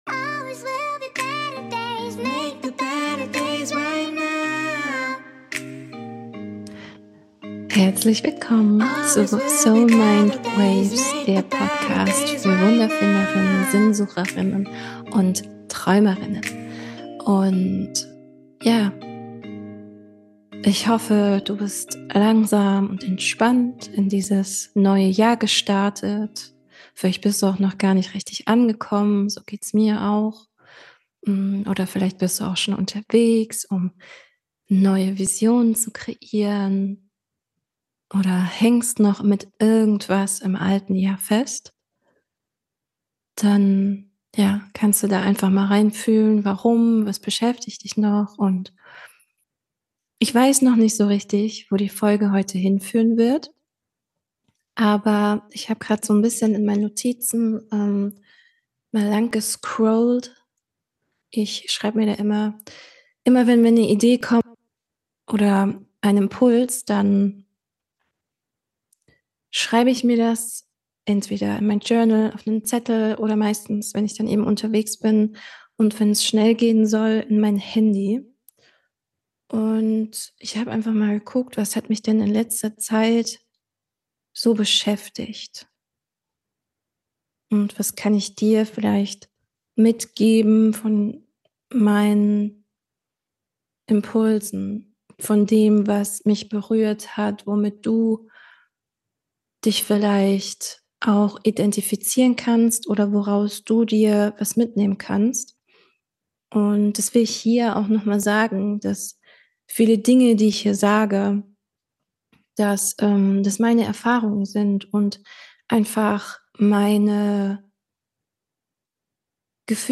Beschreibung vor 1 Jahr Letzte Gedanken & Neue Gefühle Happy New Year In dieser Folge spreche ich wieder ganz intuitiv über meine letzten Gedanken, die ich 2024 aufgeschrieben habe über meine Gefühle für 2025, ho'oponopono und Wander-Content & vieles mehr. Sprachlich wird es sehr wild bei mir, darf auch mal sein.
* P.s Ich entschuldige mich hiermit, jetzt schon für die eventuellen Störgeräusche denk dir einfach, es gehört dazu, dass ist wohl der Sound des Universums.